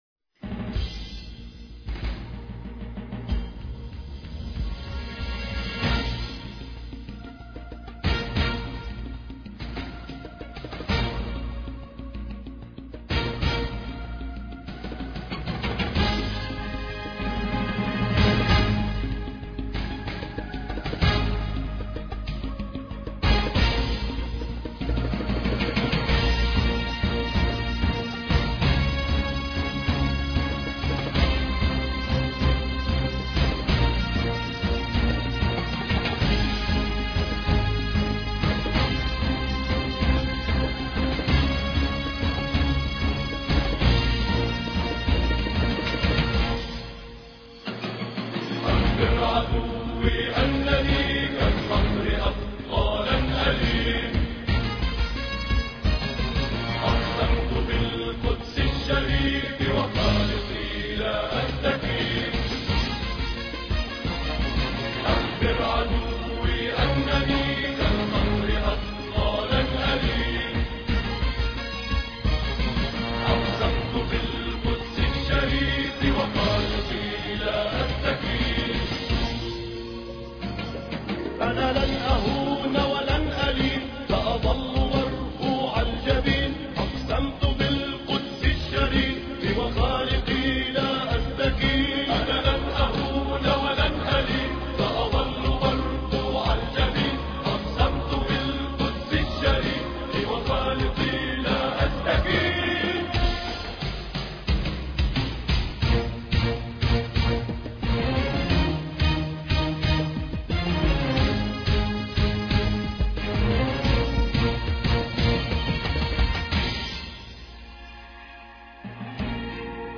لن ألين الثلاثاء 6 فبراير 2007 - 00:00 بتوقيت طهران تنزيل الحماسية شاركوا هذا الخبر مع أصدقائكم ذات صلة الاقصى شد الرحلة أيها السائل عني من أنا..